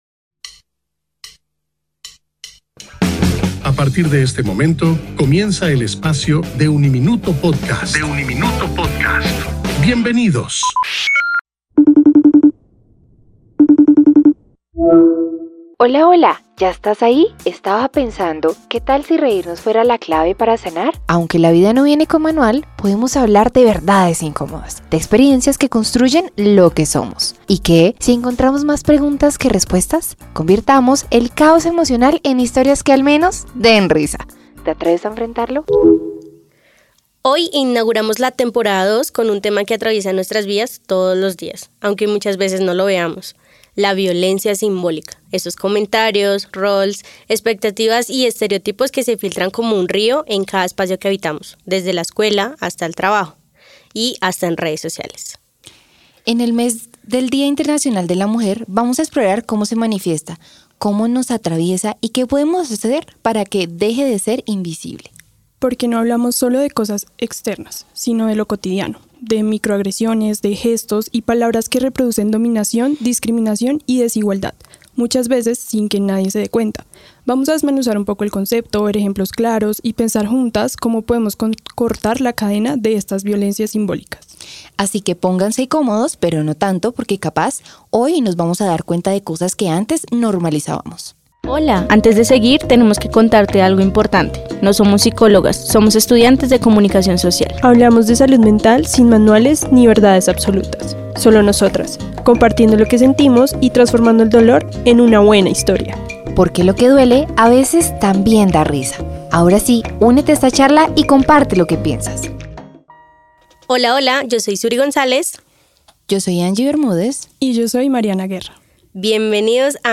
Él Árbol Rojo: conversación con la lectora y escritora en crecimiento